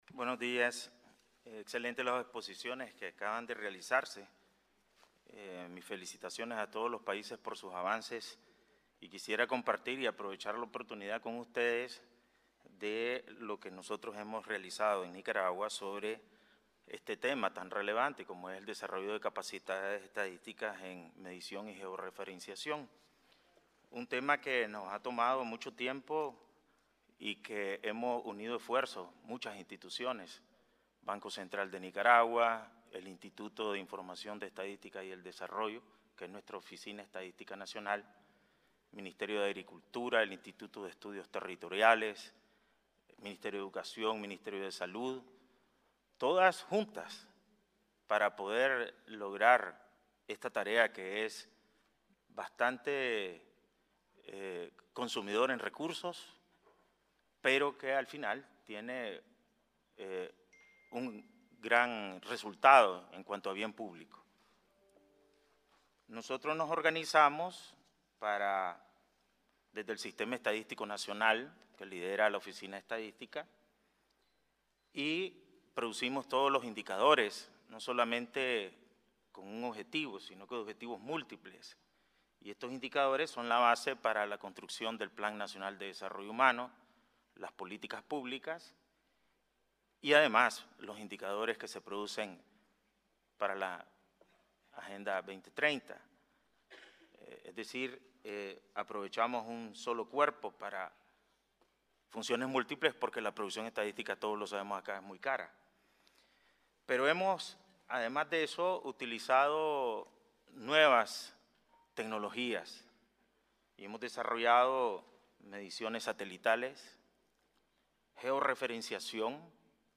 El Presidente del Banco Central de Nicaragua (BCN), Ovidio Reyes R. participó en la "Tercera Reunión del Foro de los Países de América Latina y el Caribe sobre Desarrollo Sostenible", efectuada en Santiago de Chile, del 24 al 26 de abril de 2019.
Palabras del presidente del BCN